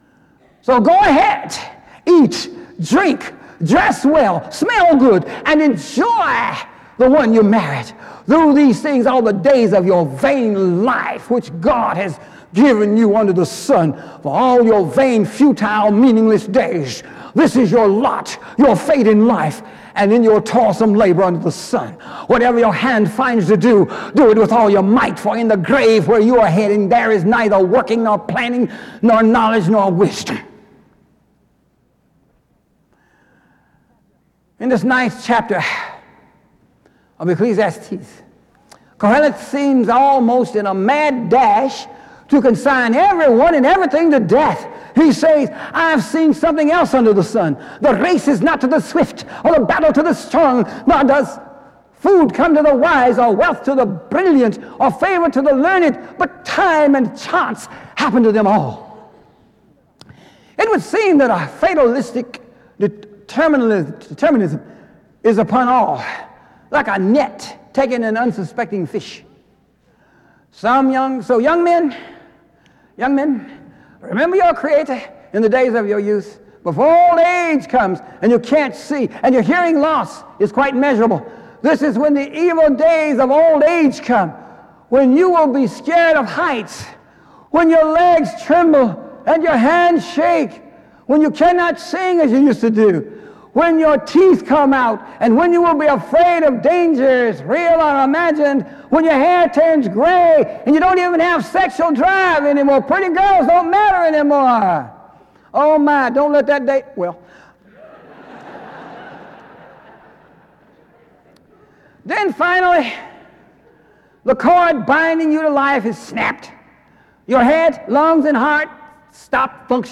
SEBTS Faculty Lecture